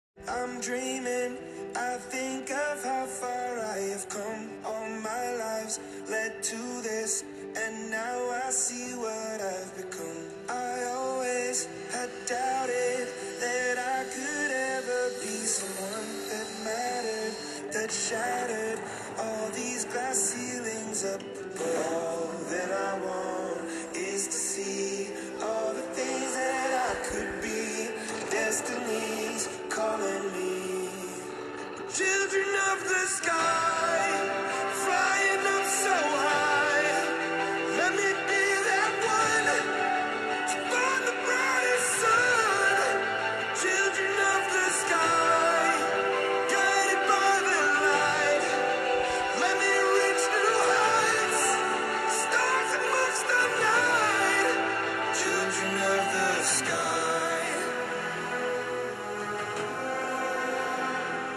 Petit passage rapide sur l’audio pour vous dire que le rendu sonore des haut-parleurs est tout à fait impressionnant !